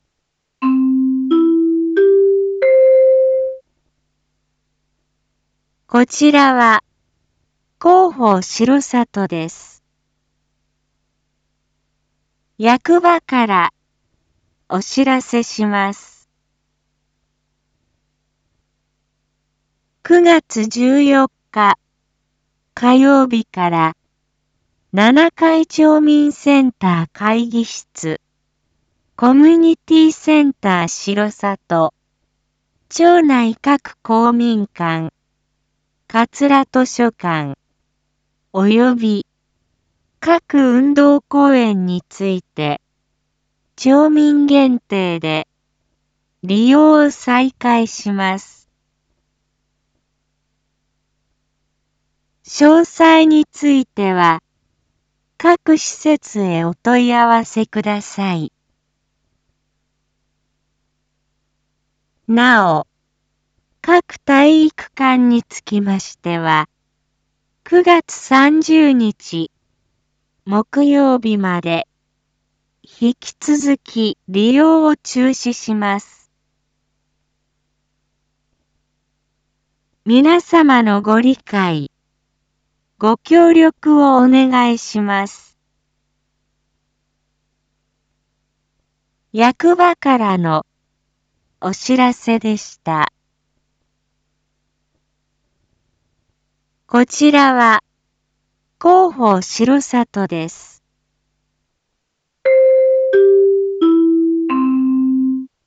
一般放送情報
Back Home 一般放送情報 音声放送 再生 一般放送情報 登録日時：2021-09-13 19:01:47 タイトル：R3.9.13 19時放送 インフォメーション：こちらは、広報しろさとです。